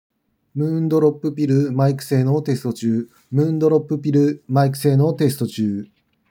マイク性能は普通より少し上
✅「水月雨 Moondrop Pill」マイク性能テスト
マイク性能は高め。変にこもった声や刺さりやすい音声になっていません。
相手の声も自然に聞こえるので聴きやすい部類のマイク性能です。